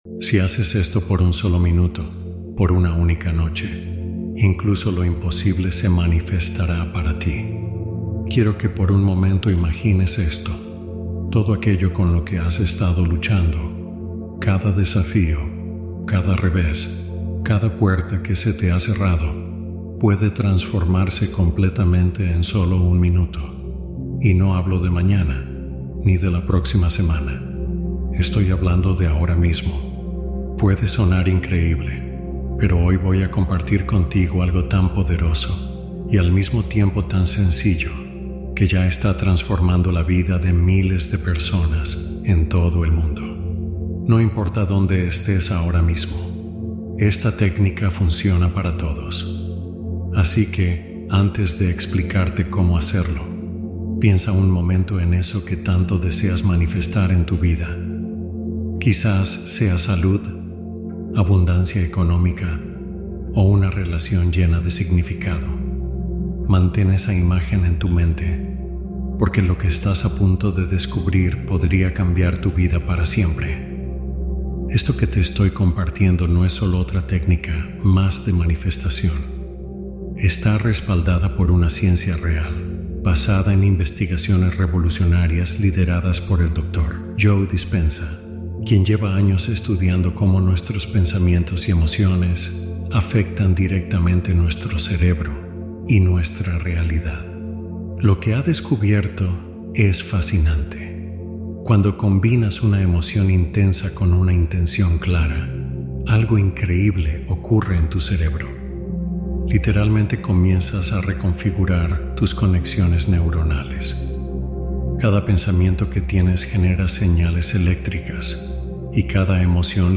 Guía de escucha nocturna para sostener deseos con apertura